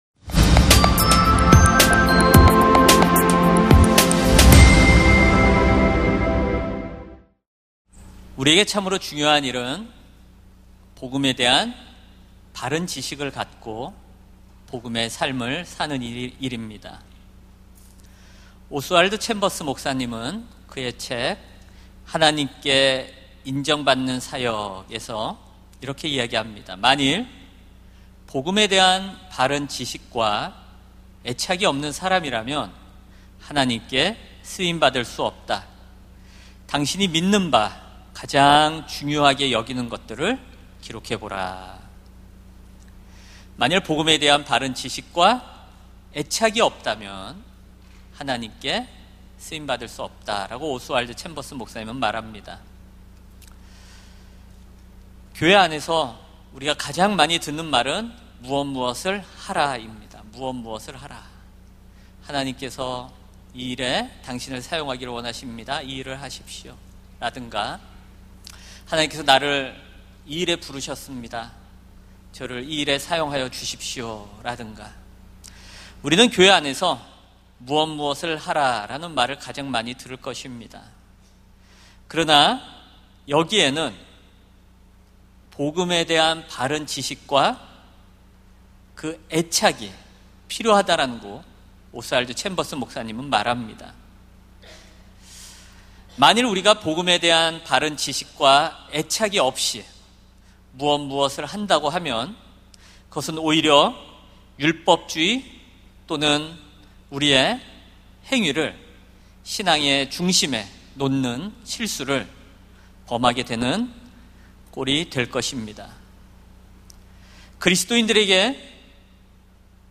설교 : 금요심야기도회